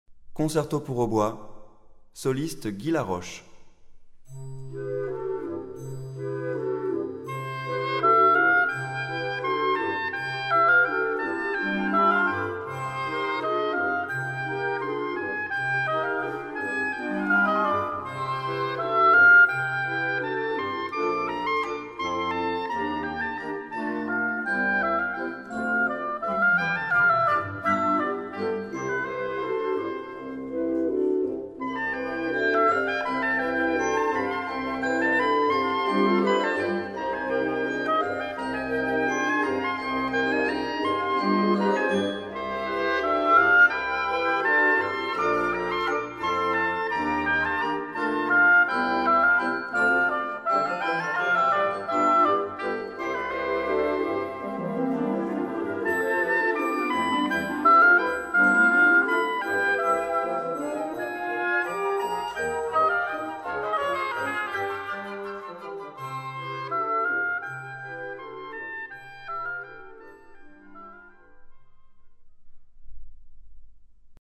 Répertoire pour Hautbois